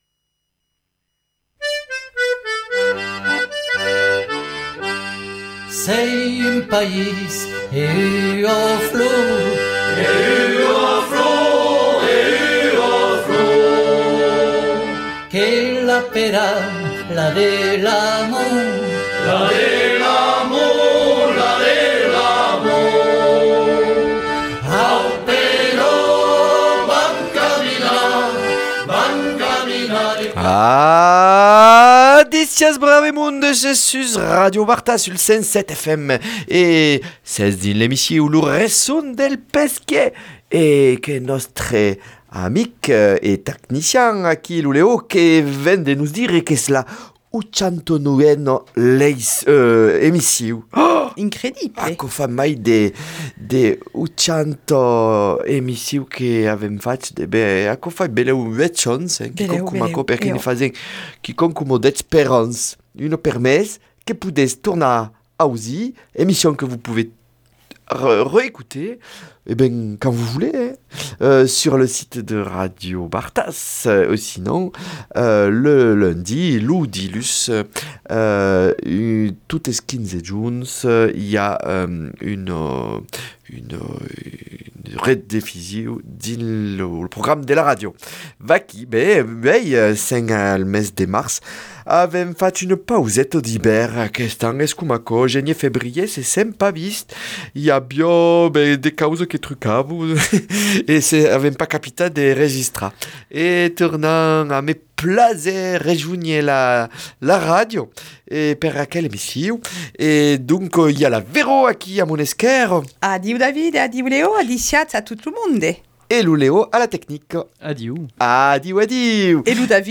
Lecture, anectodes, lecon, le tout en occitan comme toujours !